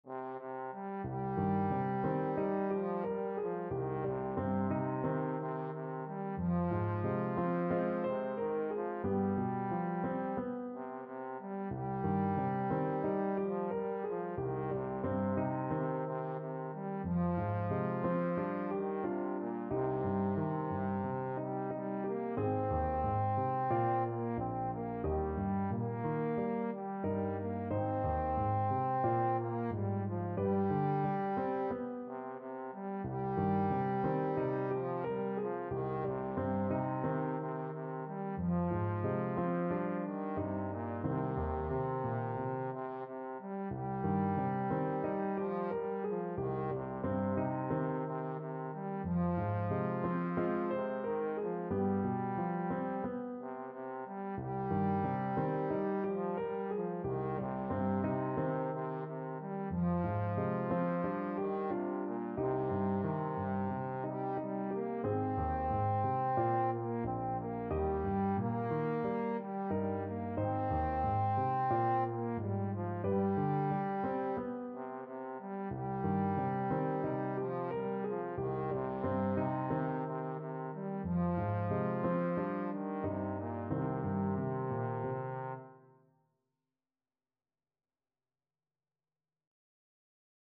4/4 (View more 4/4 Music)
Andante = c. 90